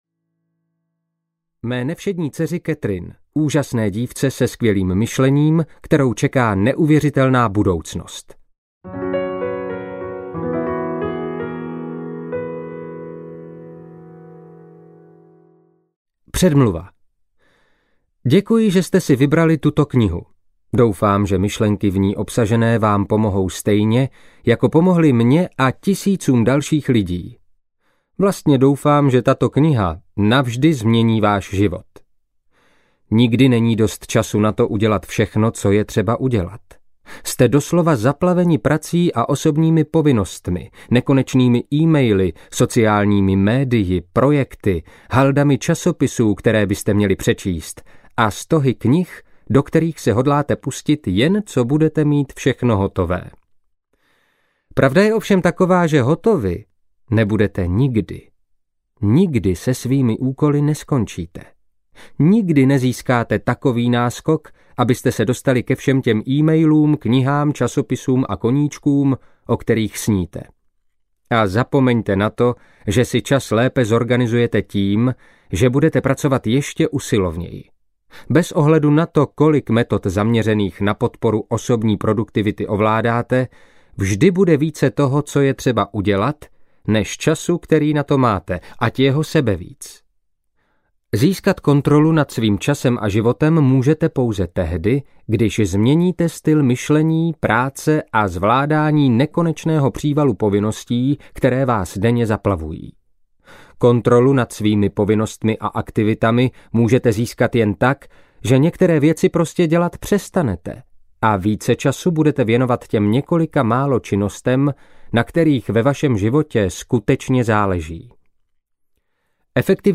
Snězte tu žábu! audiokniha
Ukázka z knihy